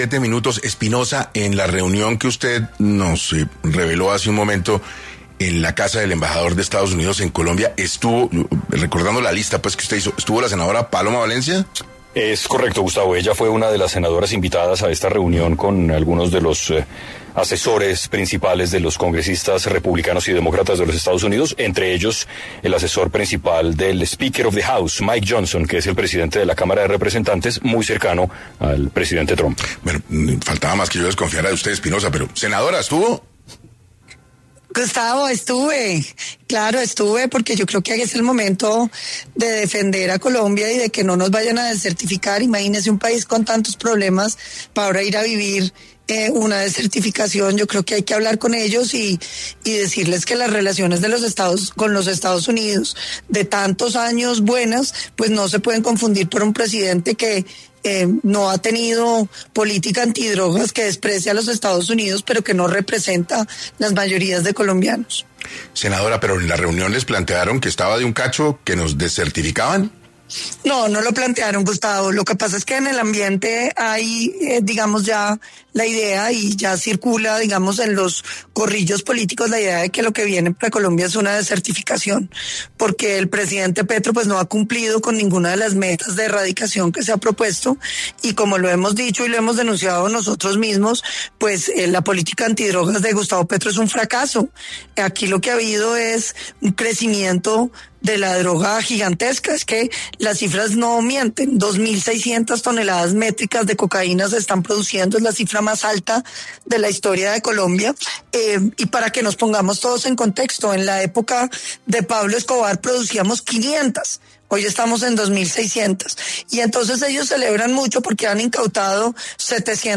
En 6AM de Caracol Radio la senadora habló sobre la reunión que llevó a cabo el embajador de EEUU con algunos congresistas colombianos sobre la actualidad del país y su relación con norteamérica